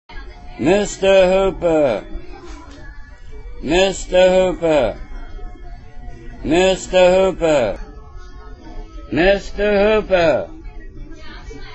He almost just drones on.
I can not remove all the ?color? in the tone to do it exactly yet. It is the right voice for the part.